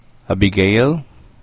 Výslovnost filipínských jmen
Naši kolegové z Filipín nám nahráli správnou výslovnost vybraných jmen.